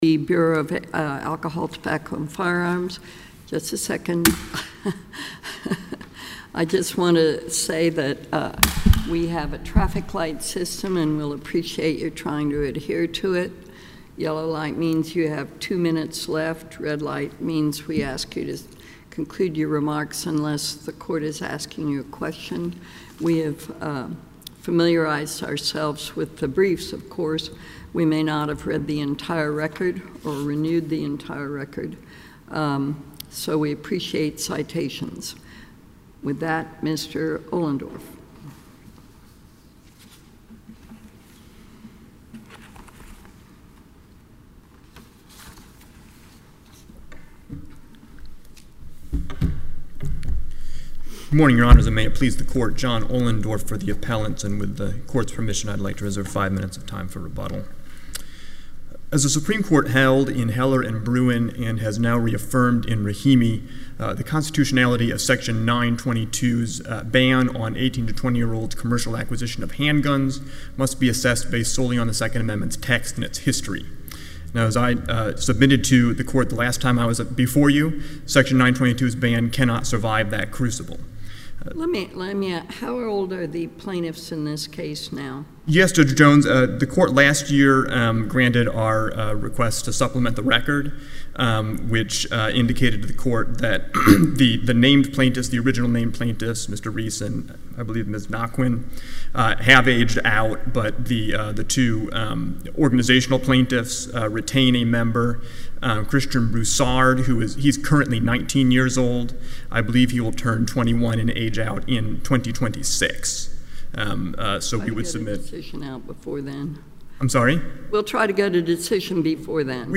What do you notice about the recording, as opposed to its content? The hearing recording is available.